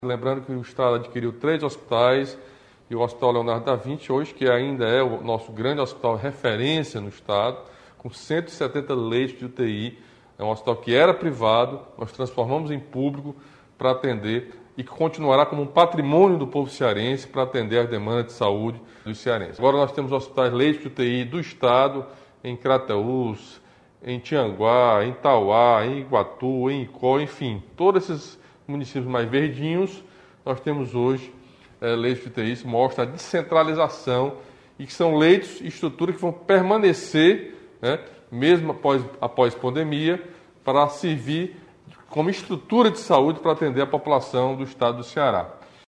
Camilo Santana destacou que houve ampliação de leitos em todo o Ceará que vão permanecer mesmo depois da pandemia.